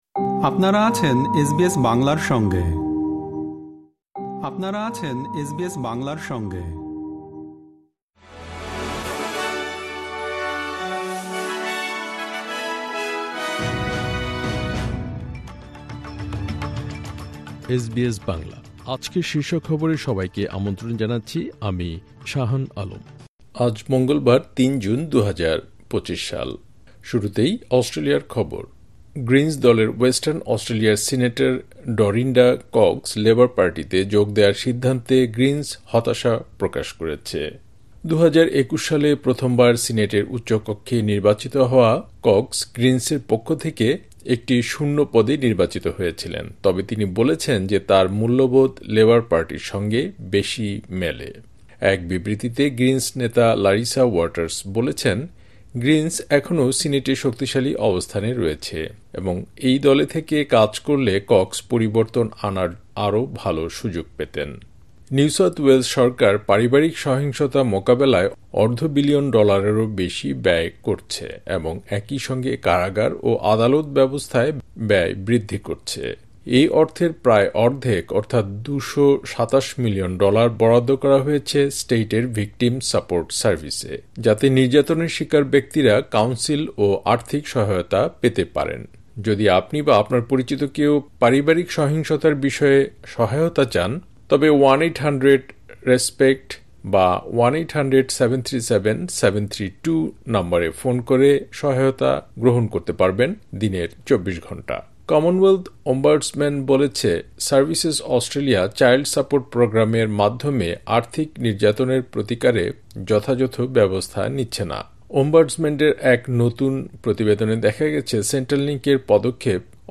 এসবিএস বাংলা শীর্ষ খবর: ৩ জুন, ২০২৫